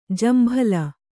♪ jambhala